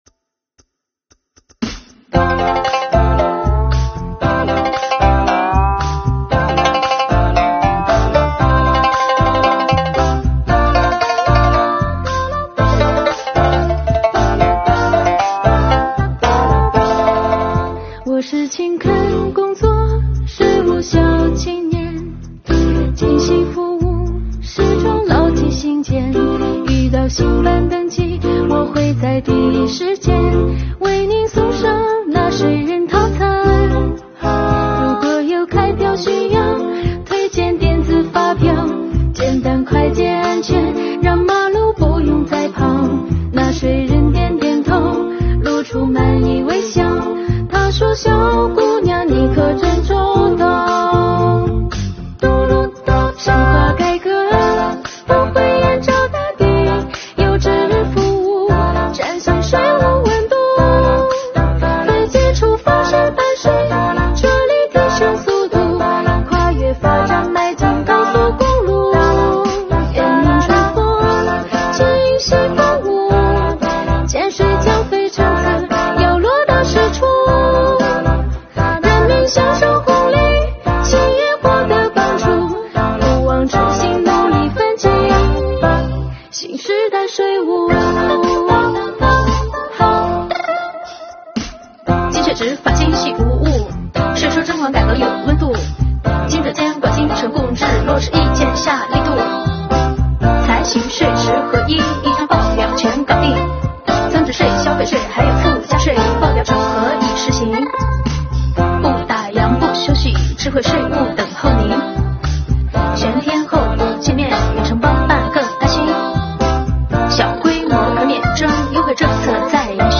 “阿卡贝拉（即无伴奏合唱）”MV
用说唱形式讲述了税务青年和创业青年为梦想努力奋斗的故事。